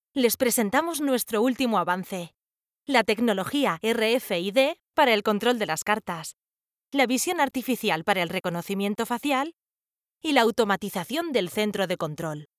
My voz es cálida, cercana, amistosa, próxima, y joven.
My voice is warm, friendly, closer, and young.
Sprechprobe: Industrie (Muttersprache):